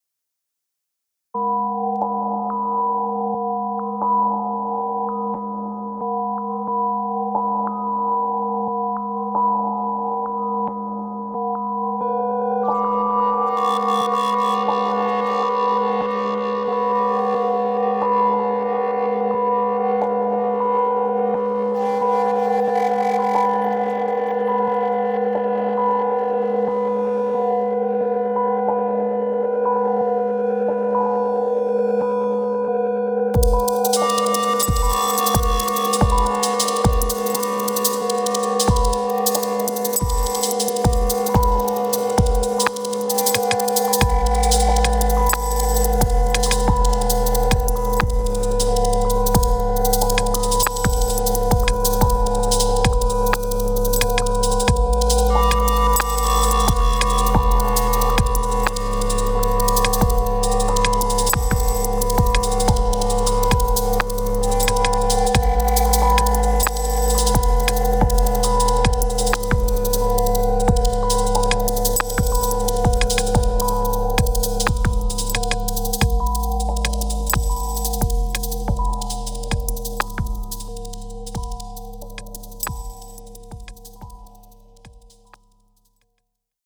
Another Prophet 6 cinematic / ambient sketch.